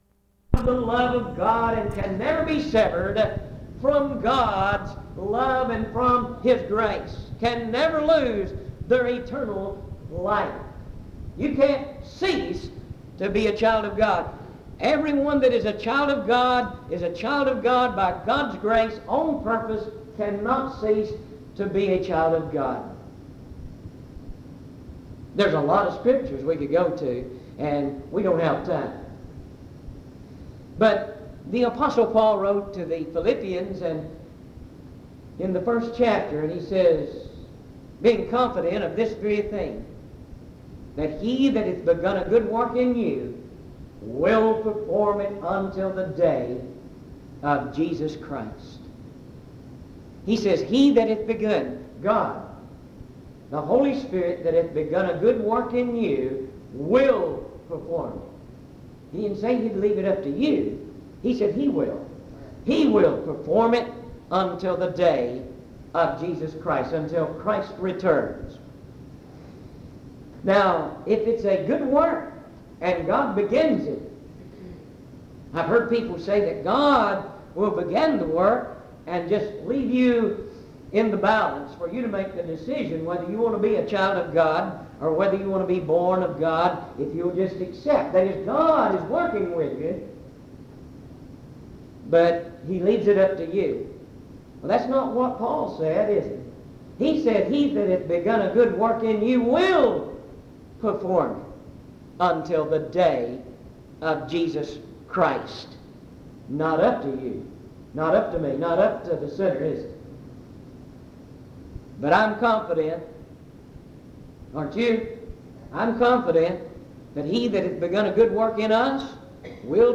Message
at Walnut Cove Primitive Baptist Church